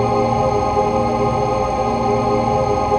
DM PAD2-89.wav